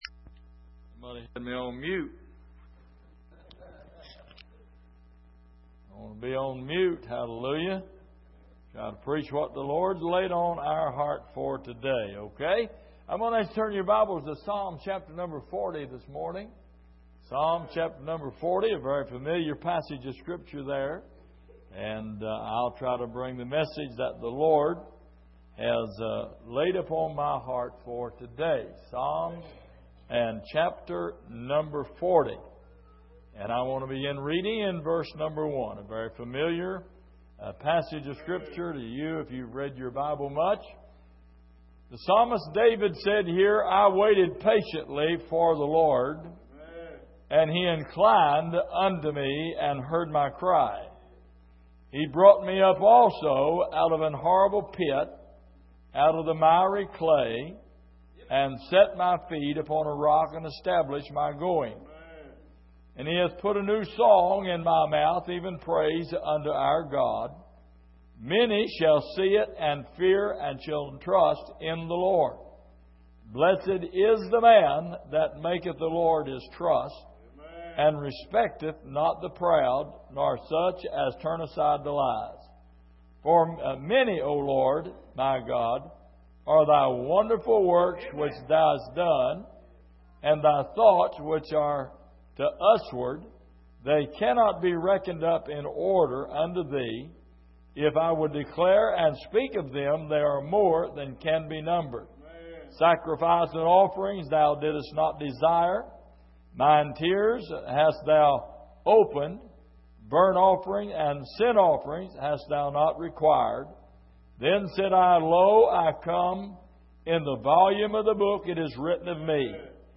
Passage: Psalm 40:1-10 Service: Sunday Morning